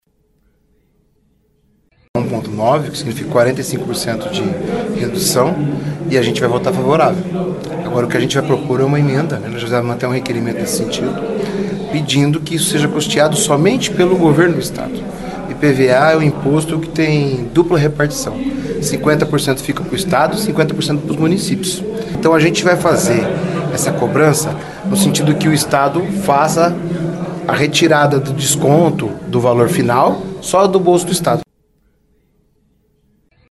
Mesmo sem a chegada oficial do projeto, deputados estaduais de oposição e situação comentaram sobre a redução de 45% durante sessão ordinária na tarde desta segunda-feira (25).